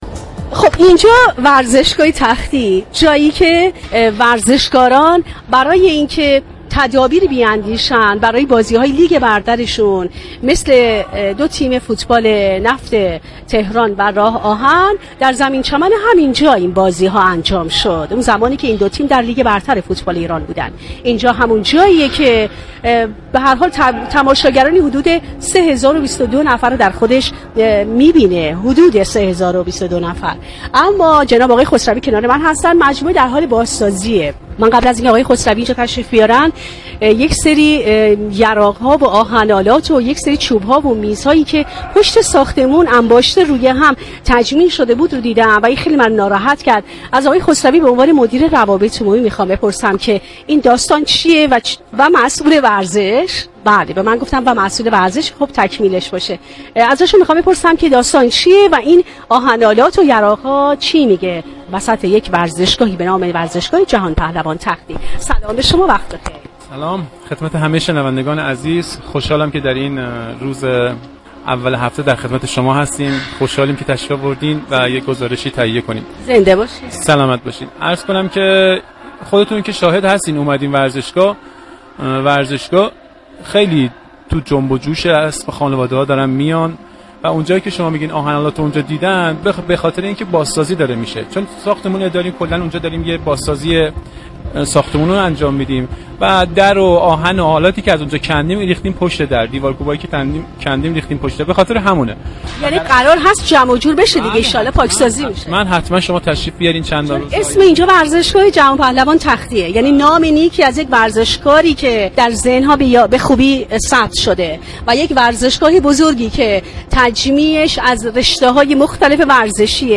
در گفت و گو با «تهران ورزشی» درخصوص روند بازسازی مجموعه ورزشی تختی